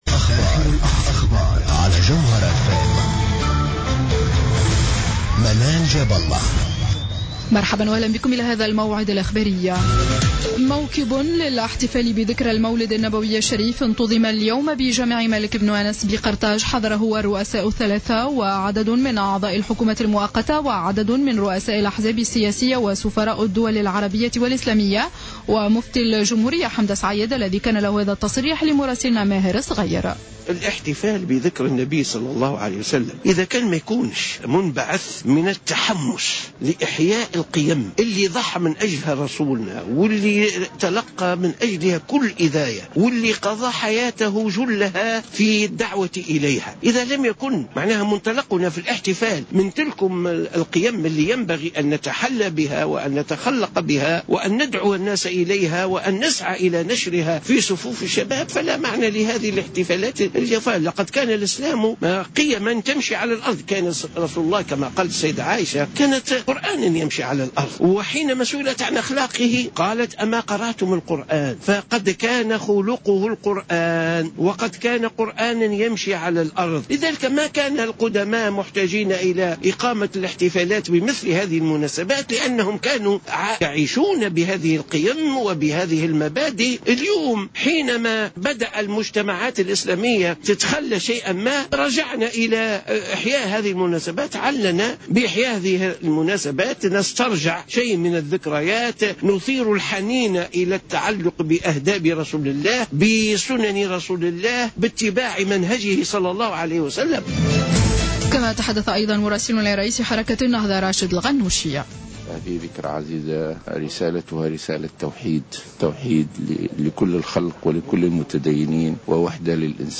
نشرة أخبار السابعة مساء ليوم الجمعة 02-01-15